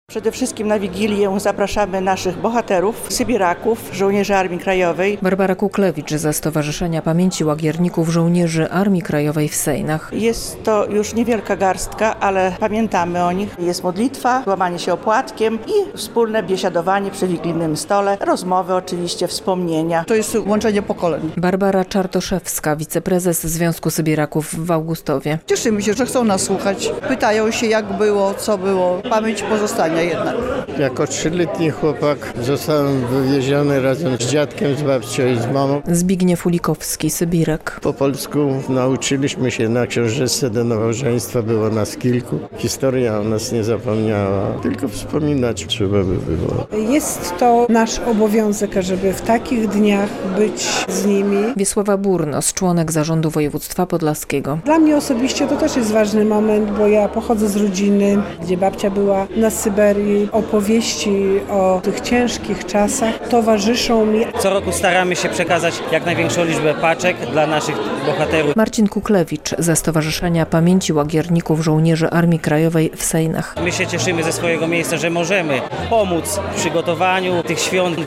W piątek (15.12) w Sejnach przy świątecznym stole, po raz kolejny spotkali się sybiracy, żołnierze AK, ich rodziny i zaproszeni goście.
relacja